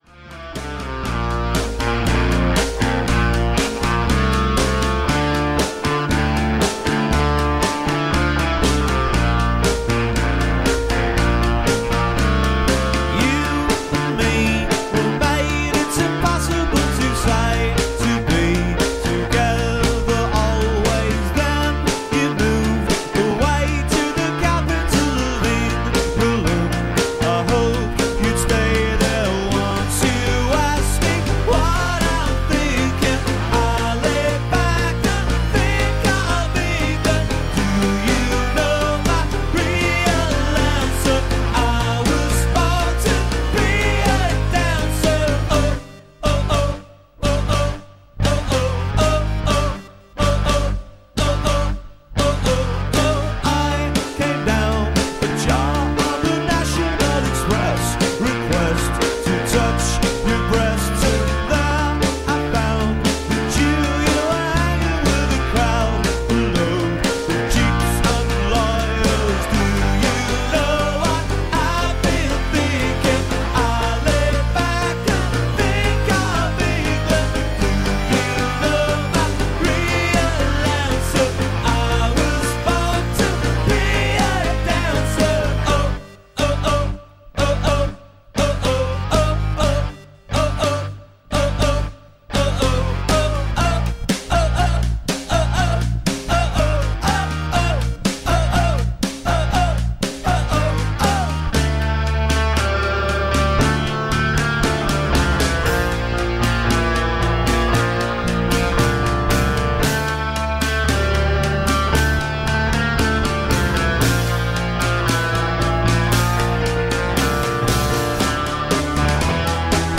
there are definite Pop leanings in their music
the songs are catchy and hook-laden